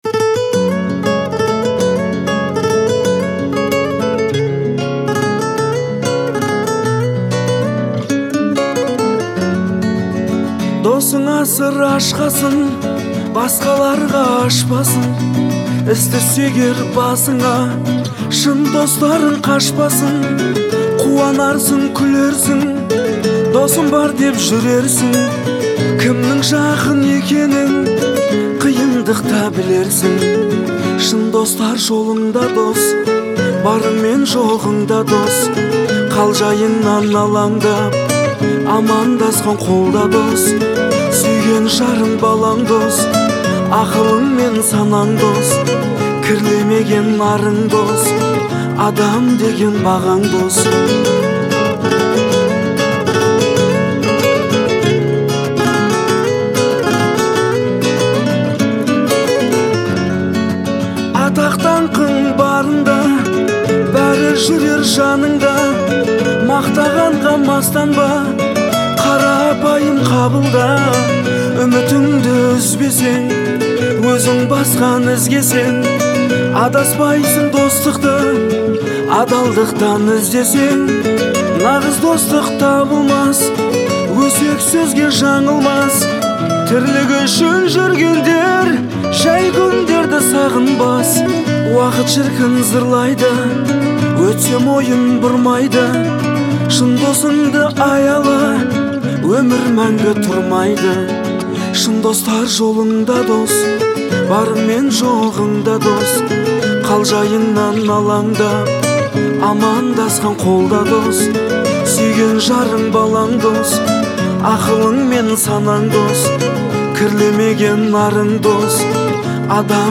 отличается мелодичностью и душевной искренностью